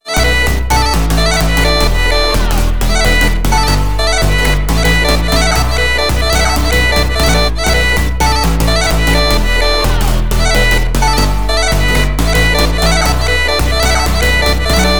From soulful vocal chops and hypnotic melodies to groovy basslines and punchy drum loops, each sample captures the essence of Ritviz’s unique style.